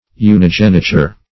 Search Result for " unigeniture" : The Collaborative International Dictionary of English v.0.48: Unigeniture \U`ni*gen"i*ture\, n. [L. unigenitus only-begotten; unus one + gignere, genitum, to beget.] The state of being the only begotten.